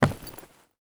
0335ec69c6 Divergent / mods / Soundscape Overhaul / gamedata / sounds / material / human / step / new_wood3.ogg 29 KiB (Stored with Git LFS) Raw History
new_wood3.ogg